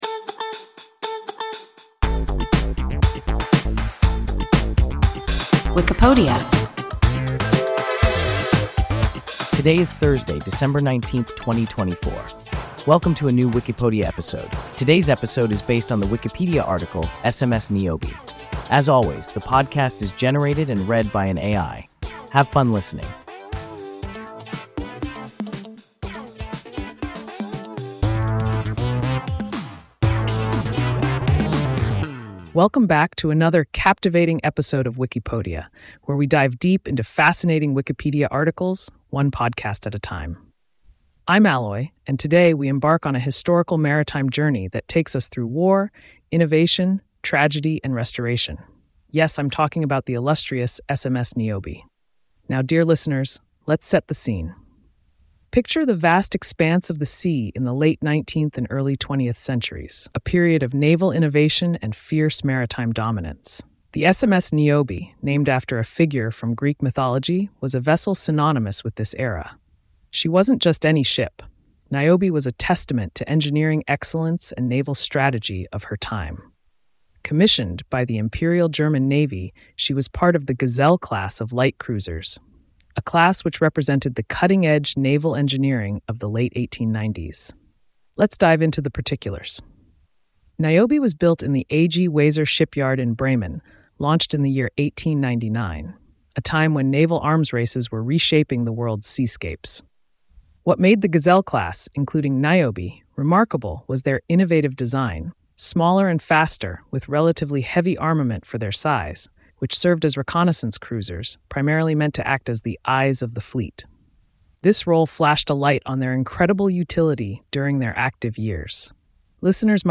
SMS Niobe – WIKIPODIA – ein KI Podcast